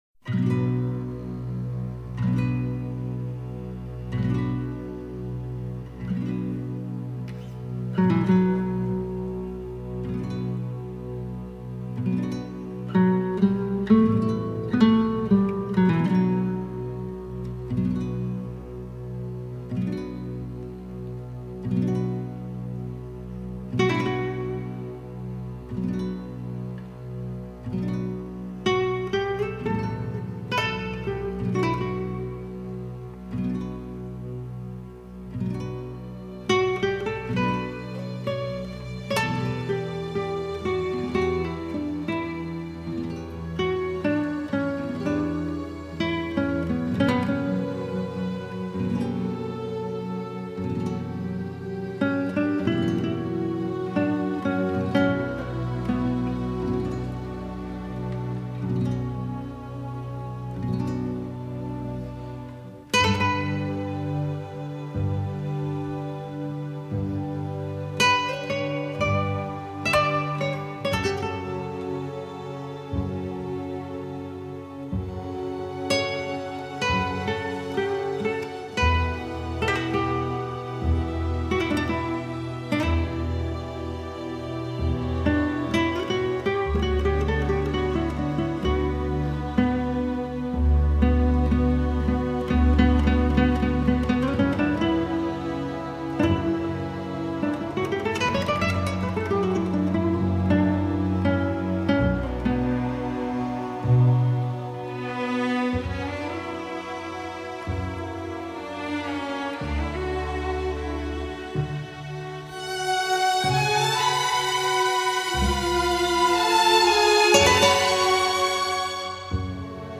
资源类型：纯音乐/吉他 音乐流派：古典柔美